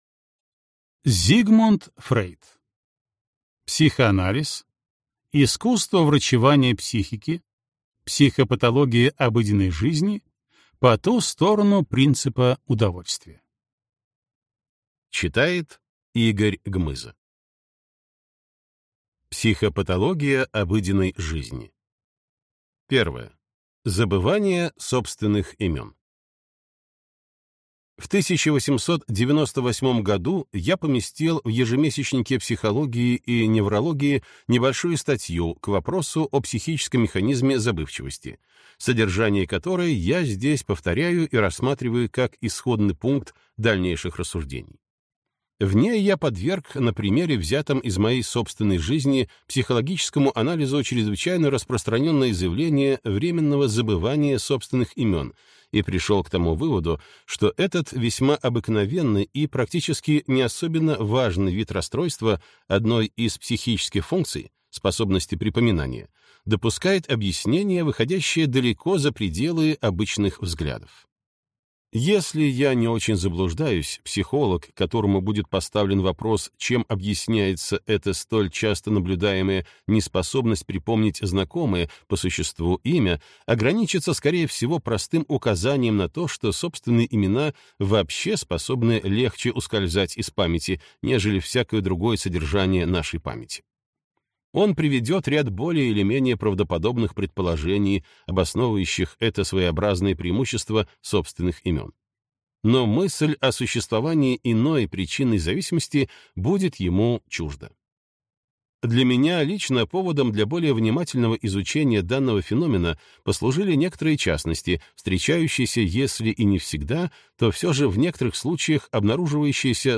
Аудиокнига Психоанализ. Искусство врачевания психики. Психопатология обыденной жизни. По ту сторону принципа удовольствия | Библиотека аудиокниг